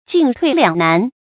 注音：ㄐㄧㄣˋ ㄊㄨㄟˋ ㄌㄧㄤˇ ㄣㄢˊ
進退兩難的讀法